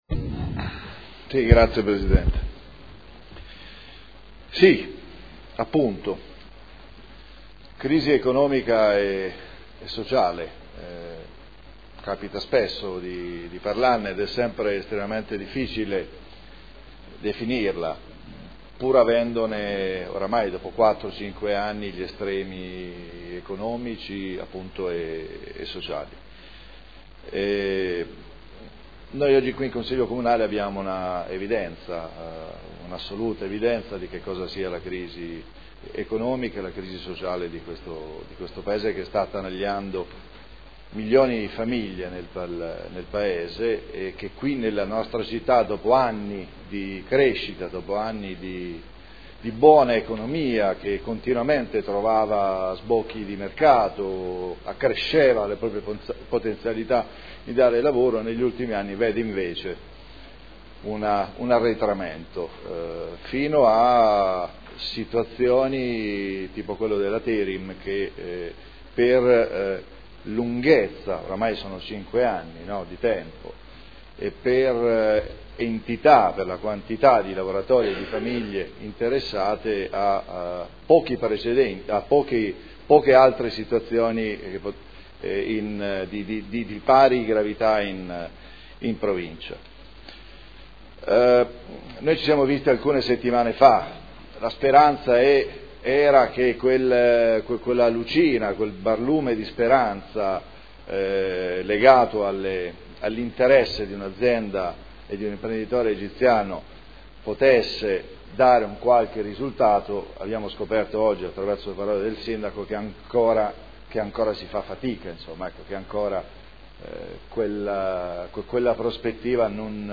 Paolo Trande — Sito Audio Consiglio Comunale
Dibattito su interrogazione dei consiglieri Ricci (Sinistra per Modena), Trande (P.D.) avente per oggetto: “Salvare TERIM”